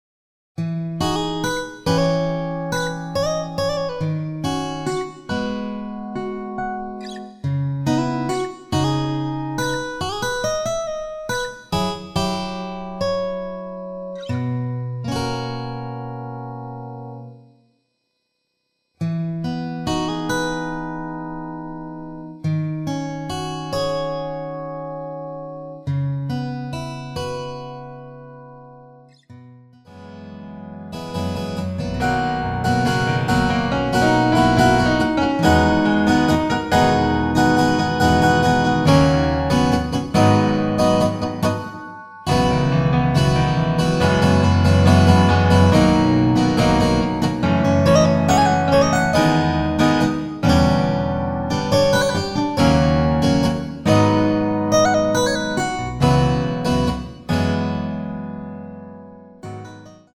키 E 가수
원곡의 보컬 목소리를 MR에 약하게 넣어서 제작한 MR이며